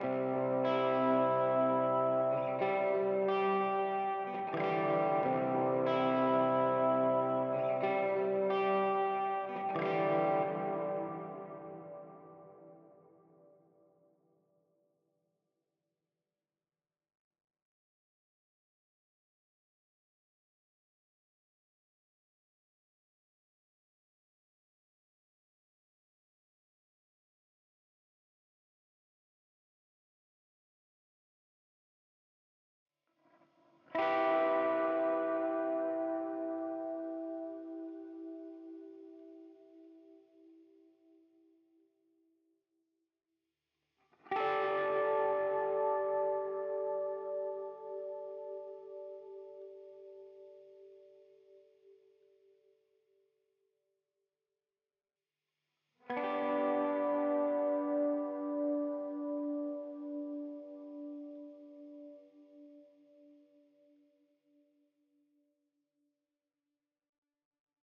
Chords.wav